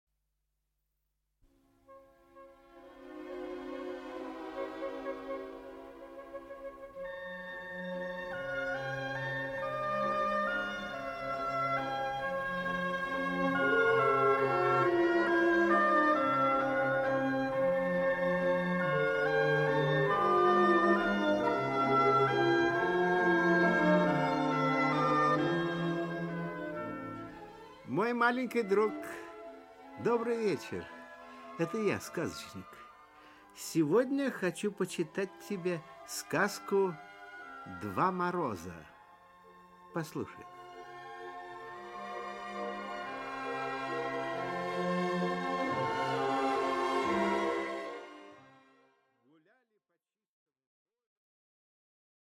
Аудиокнига Два Мороза | Библиотека аудиокниг
Aудиокнига Два Мороза Автор Народное творчество Читает аудиокнигу Николай Литвинов.